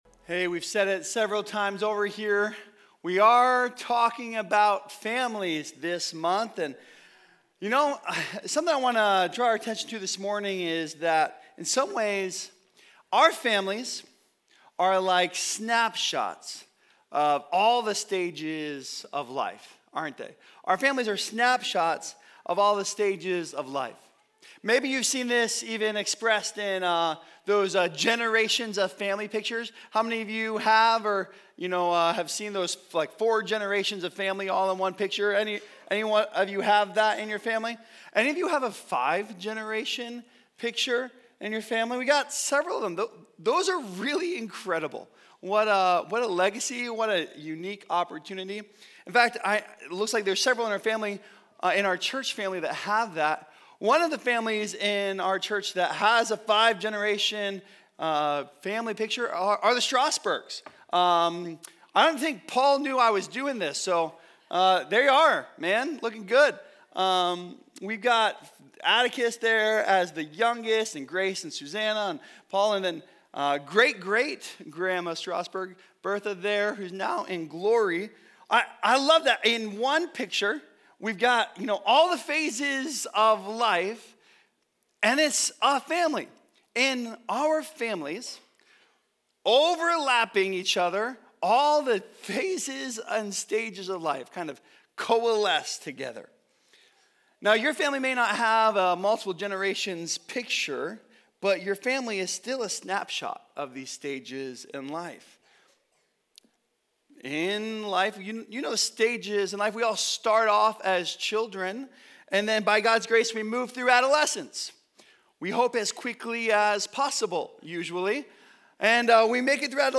The Stages of the Gospel in Every Stage of Life | Every Family Has Crazy - HP Campus Sermons